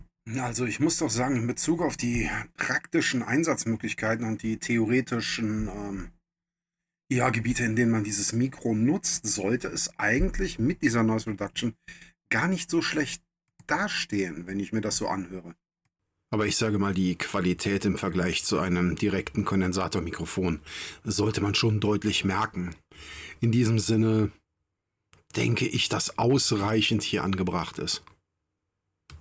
Es bietet eine klare Sprachaufnahme und gibt mir ein gutes Gefühl. Nutzen wir es alledings im Podcast Bereich und stellen im direkten Vergleich das Avermedia Streaming Mikrofon (hier im Test) dagegen fällt das nacon Streamer Microphone leider stark ab.